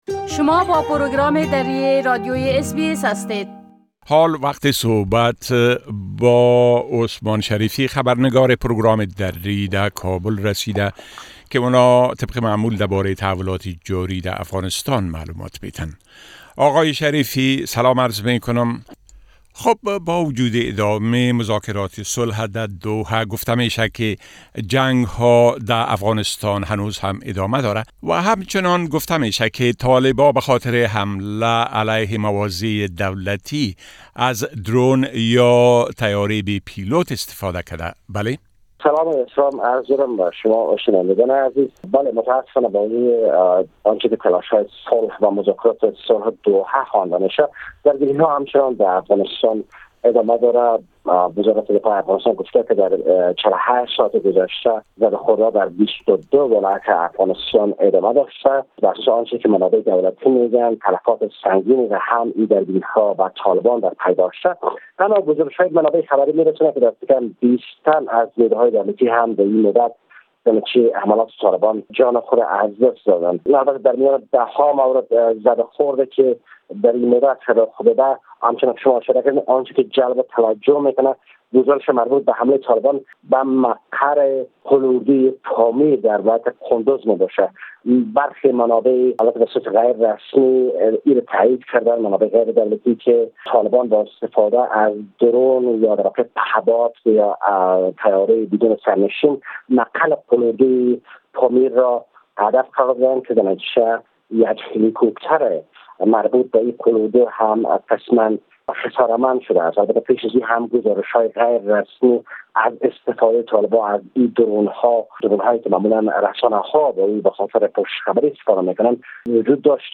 گزارش كامل خبرنگار ما در كابل، به شمول اوضاع امنيتى و تحولات مهم ديگر در افغانستان، را در اينجا شنيده ميتوانيد.